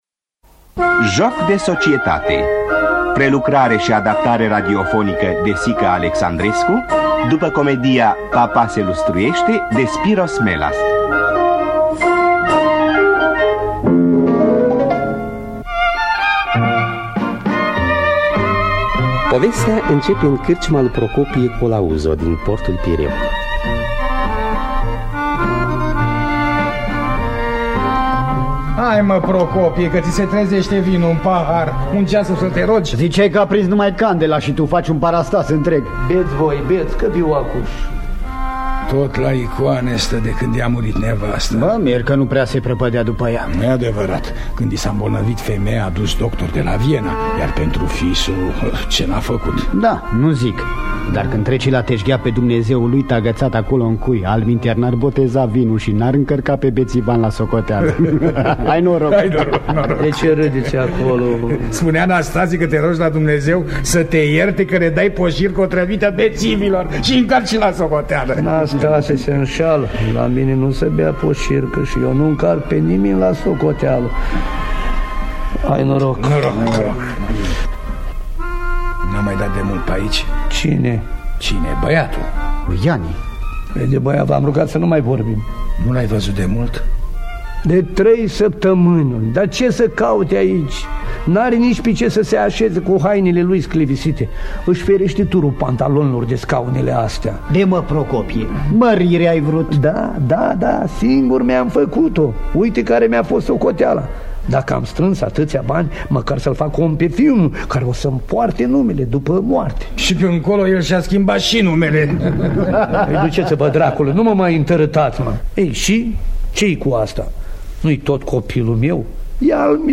Joc de societate de Spyros Melas – Teatru Radiofonic Online